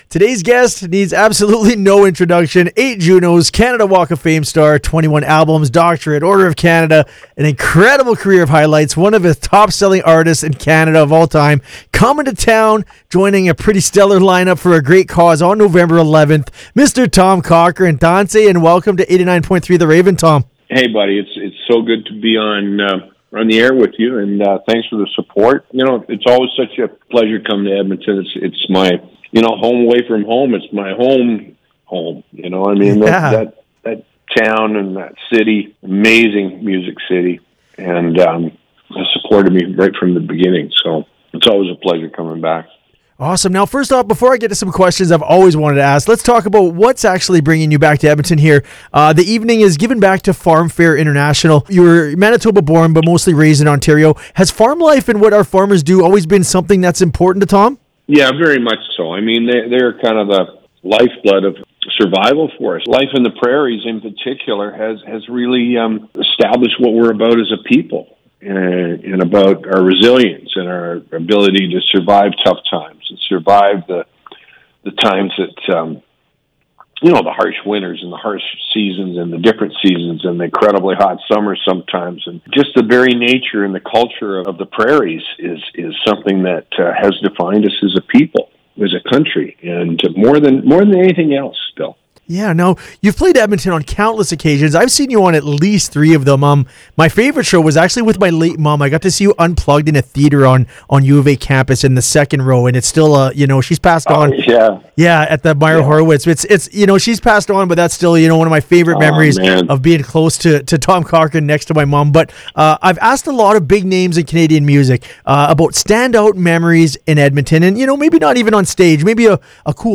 They chat for over 20 minutes about Remembrance Day, the highway named after him, his favorite Cochrane song , along with his fond memories of Edmonton.